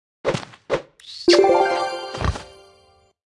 Media:Sfx_Anim_Super_Hog Rider.wavMedia:Sfx_Anim_Ultra_Hog Rider.wavMedia:Sfx_Anim_Ultimate_Hog Rider.wav 动作音效 anim 在广场点击初级、经典、高手、顶尖和终极形态或者查看其技能时触发动作的音效
Sfx_Anim_Super_Hog_Rider.wav